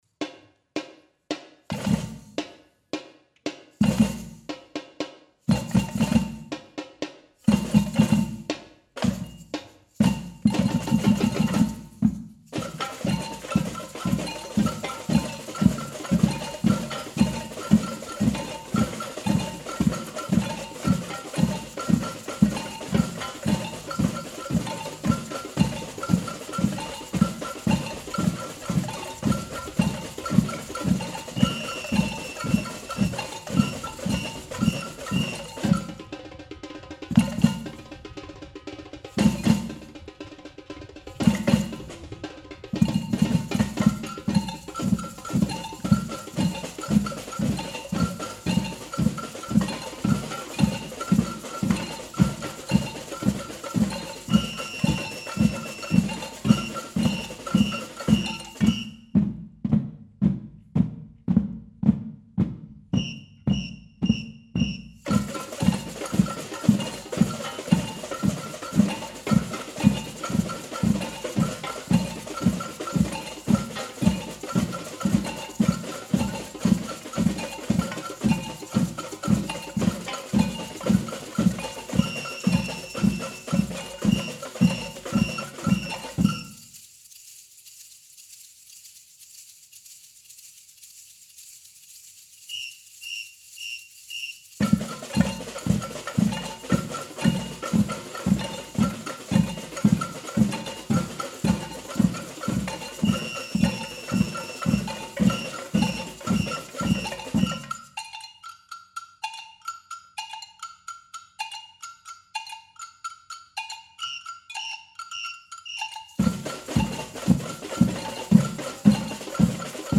Jeder Teilnehmer durfte sich ein Instrument aussuchen und erst einmal selbstständig drauf los “grooven”.
ein Tonaufnahme eines kompletten Samba Batucada Stücks mit Anfang, Groove- und- Solo-Teilen